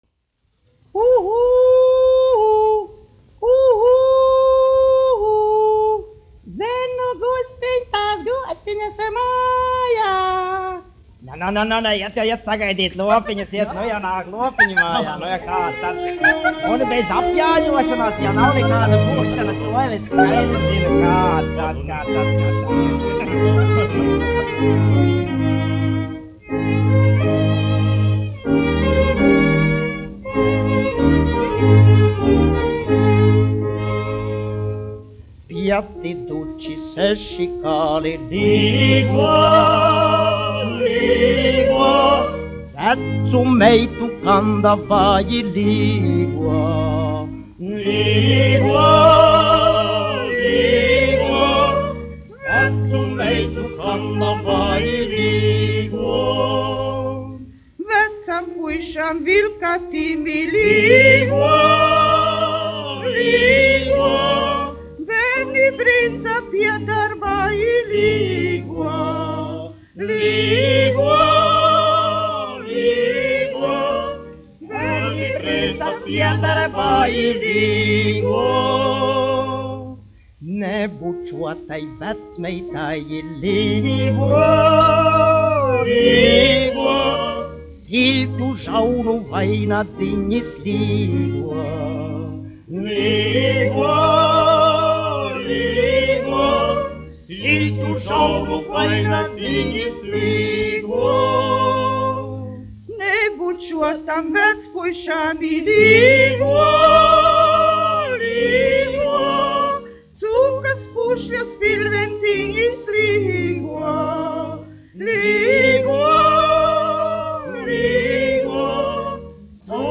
1 skpl. : analogs, 78 apgr/min, mono ; 25 cm
Latviešu tautasdziesmas
Vokālie kvarteti